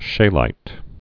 (shālīt, shē-)